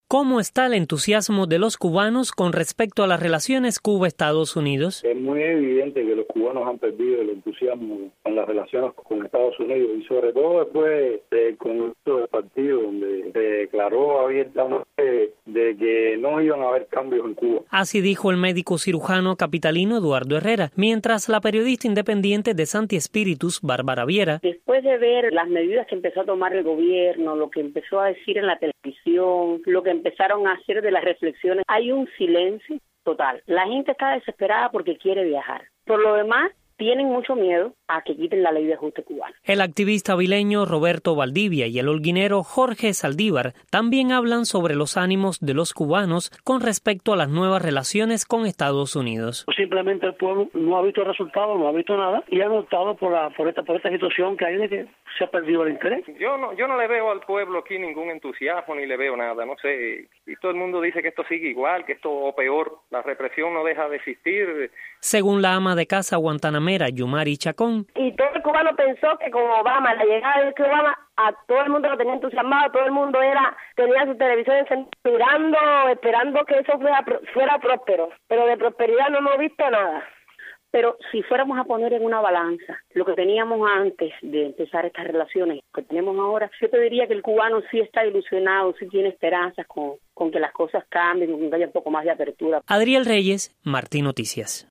Noticias de Radio Martí
Cubanos de diferentes puntos de la isla comentan las nuevas relaciones entre Cuba y Estados Unidos.